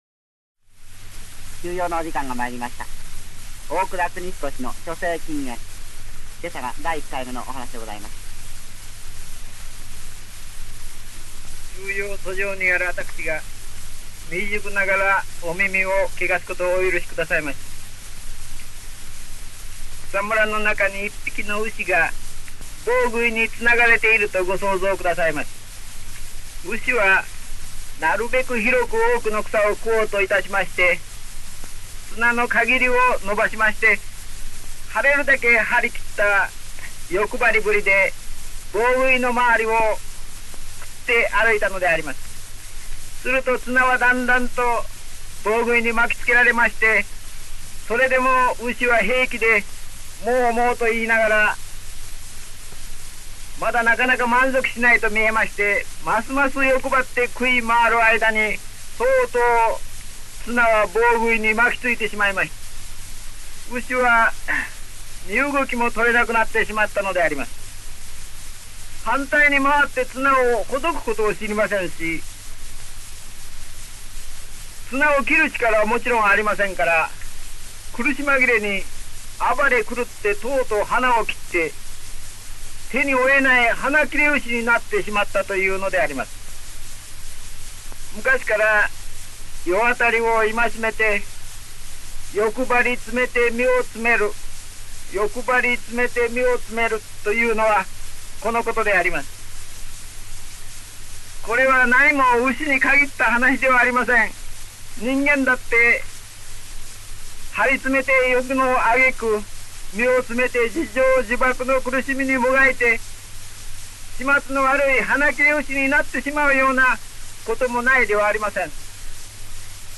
本音声は、その第１回目（昭和12年3月25日）のラジオ放送を録音したSP盤レコードをデジタル化したものです。